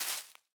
Minecraft Version Minecraft Version snapshot Latest Release | Latest Snapshot snapshot / assets / minecraft / sounds / block / leaf_litter / place3.ogg Compare With Compare With Latest Release | Latest Snapshot